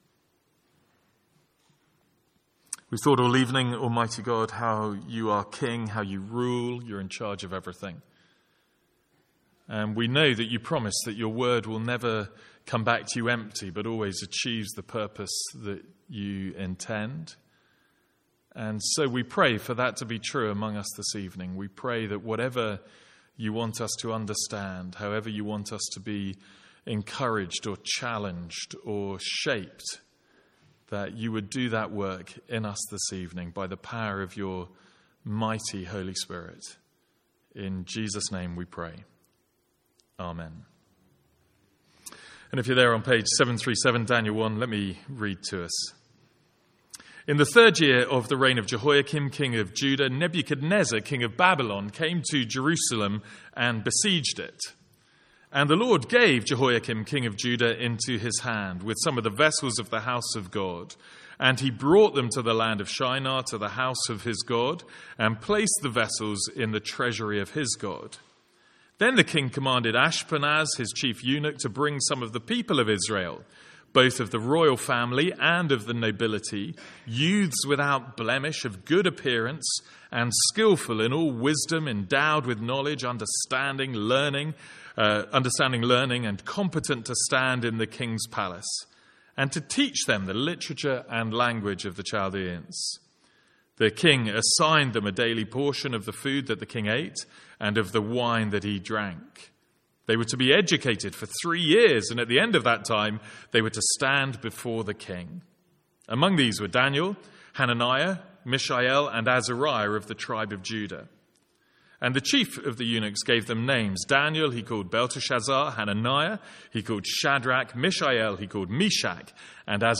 Sermons | St Andrews Free Church
From our evening series in Daniel.